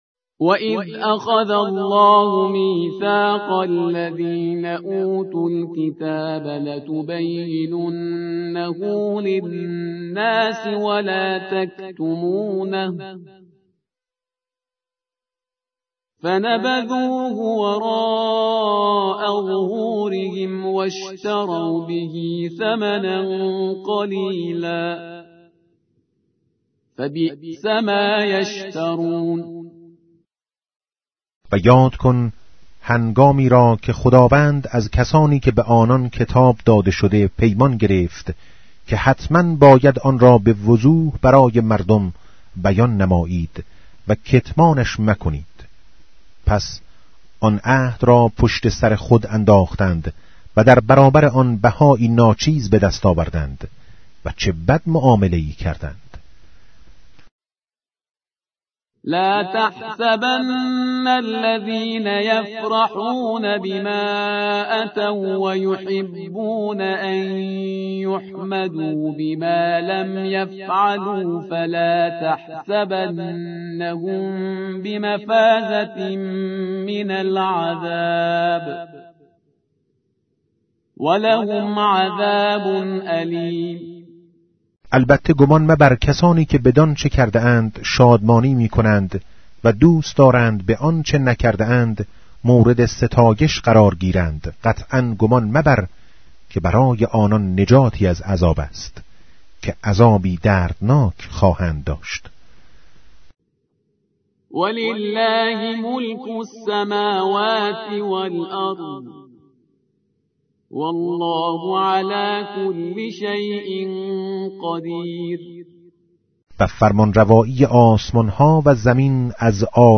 به گزارش آوای سیدجمال، از آنجا که پیامبر اکرم(ص)، در آخرین وصیت خود، از قرآن به‌عنوان ثقل اکبر یاد کرده و تأکید بر توجه به این سعادت بشری داشت، بر آن شدیم در بخشی با عنوان «کلام نور» تلاوتی از چراغ پرفروغ قرآن كه تلألو آن دل‌های زنگار گرفته و غفلت زده را طراوتی دوباره می‌بخشد به صورت روزانه تقدیم مخاطبان خوب و همیشه همراه آوای سیدجمال کنیم.